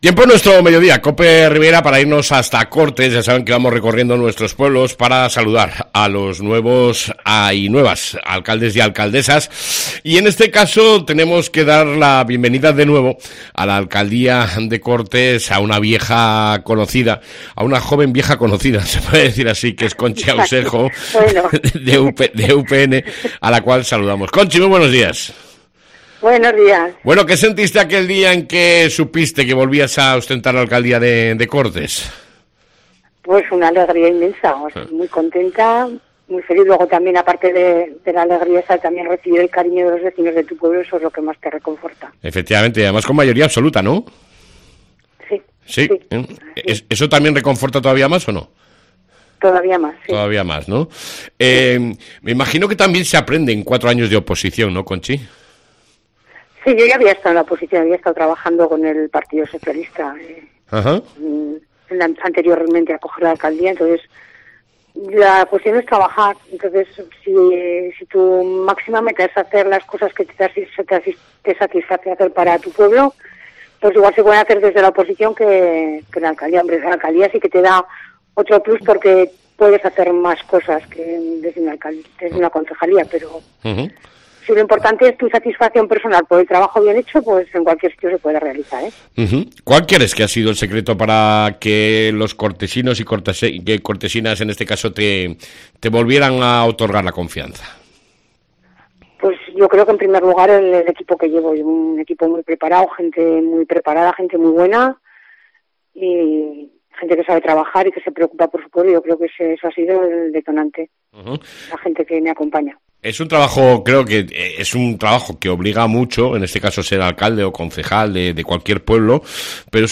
ENTREVISTA CON LA ALCALDESA DE CORTES , CONCHI AUSEJO